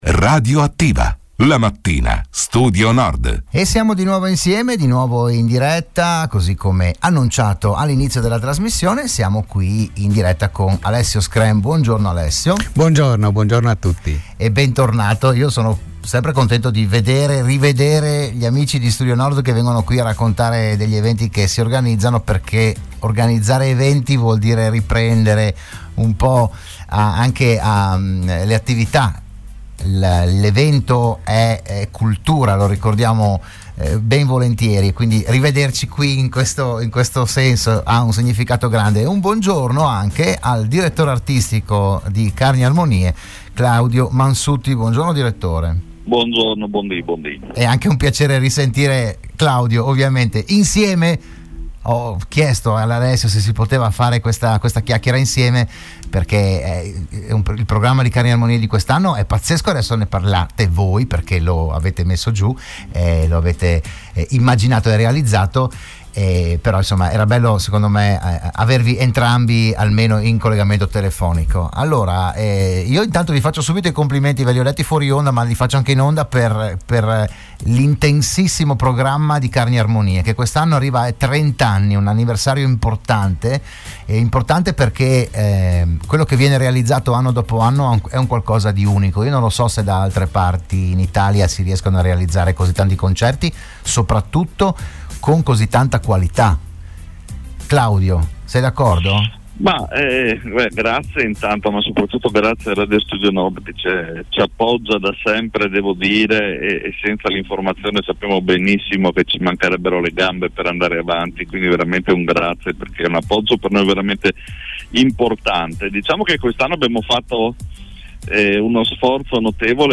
Della manifestazione si è parlato oggi a “RadioAttiva“, la trasmissione di Radio Studio Nord